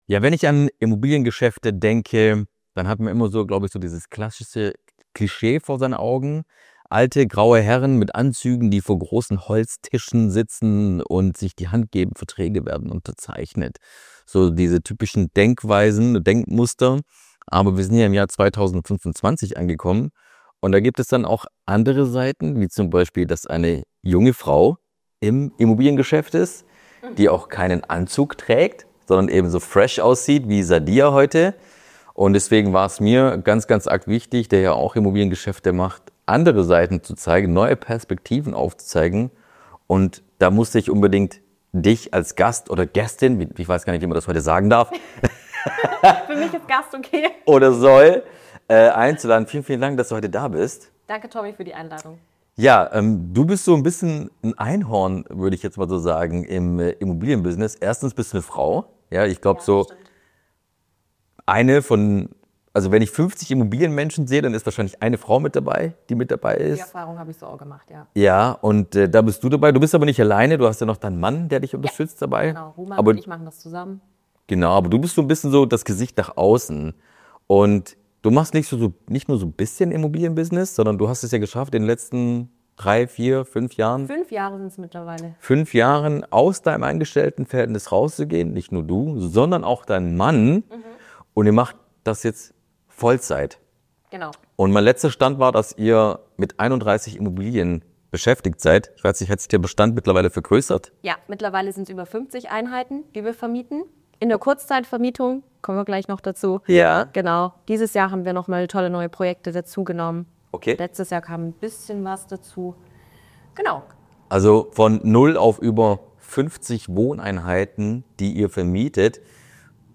Ein ehrlicher Talk über Unternehmertum ohne Besitz, echte finanzielle Freiheit und den Mut, gegen den Strom zu schwimmen.